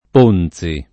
Ponzi [ p 1 n Z i ]